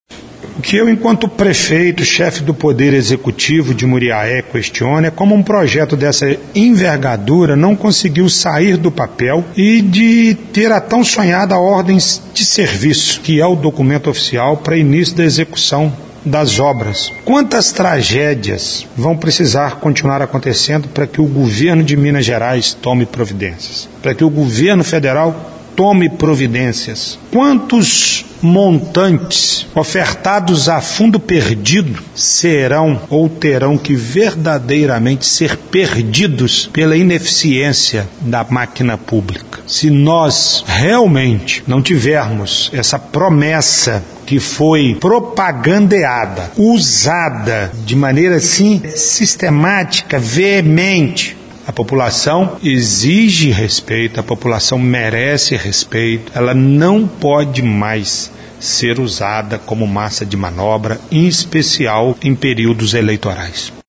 prefeito Grego da cidade de Muriaé (MG)